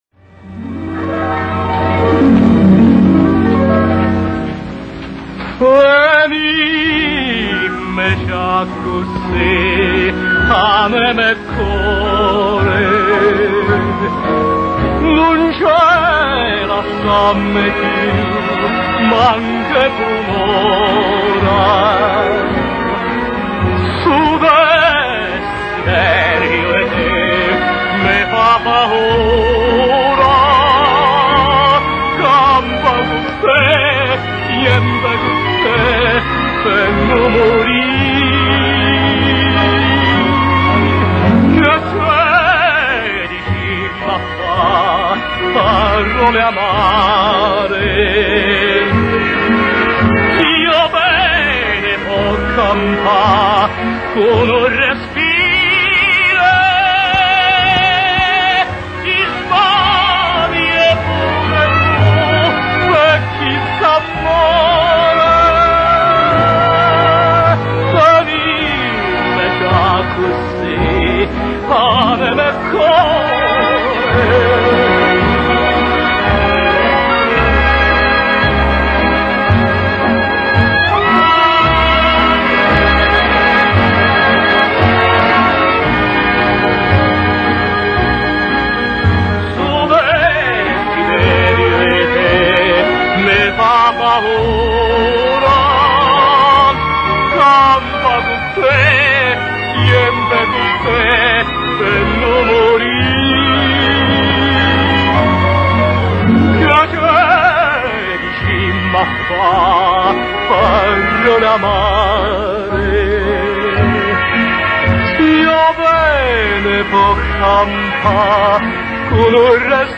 singing the beautiful song "